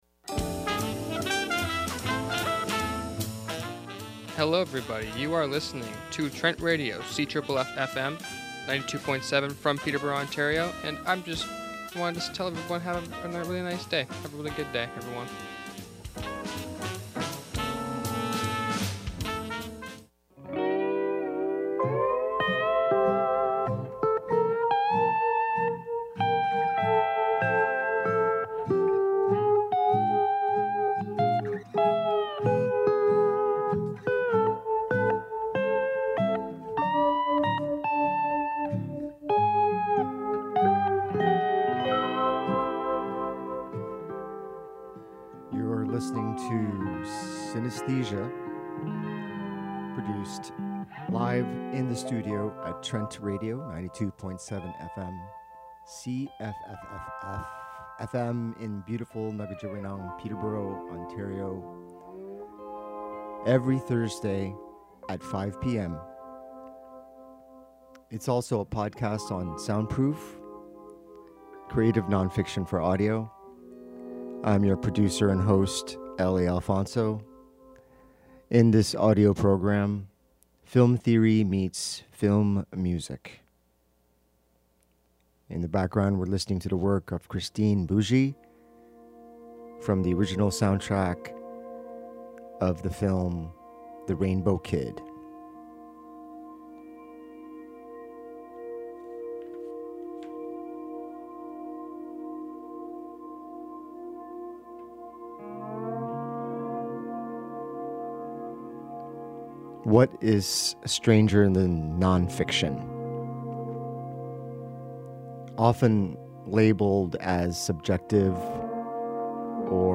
Clips from the documentary Keyboard Fantasies 13.